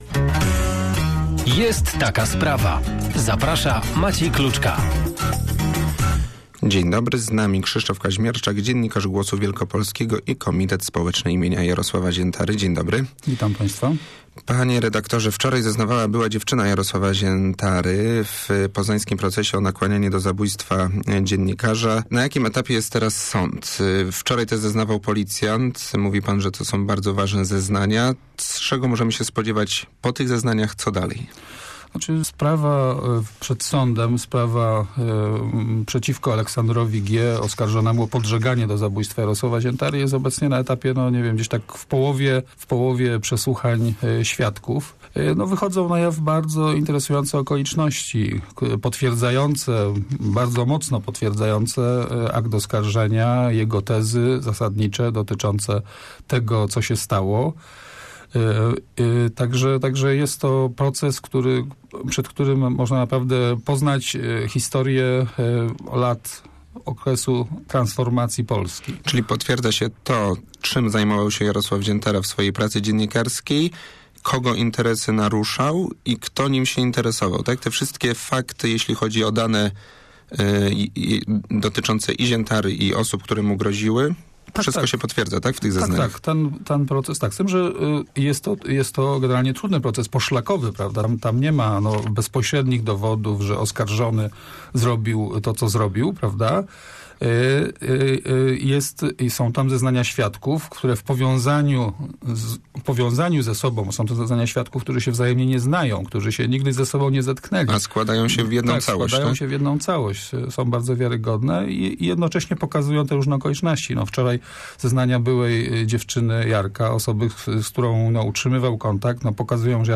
(cała rozmowa poniżej)